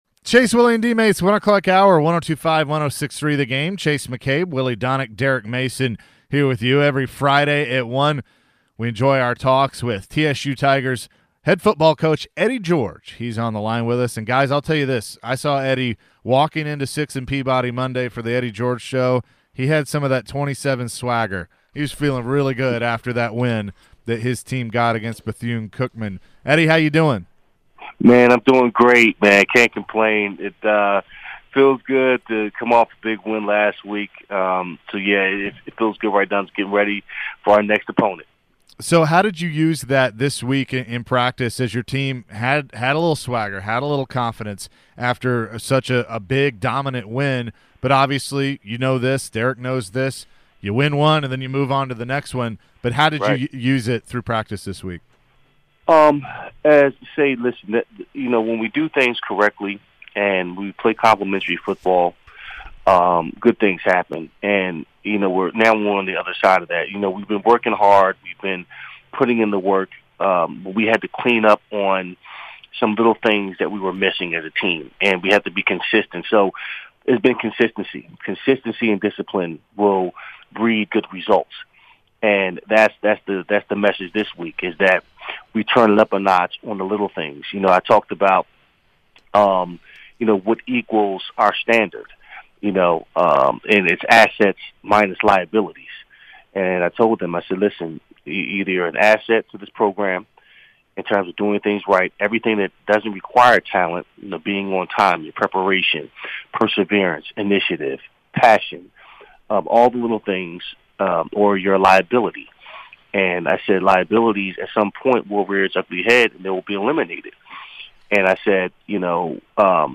Eddie George interview 10-14-22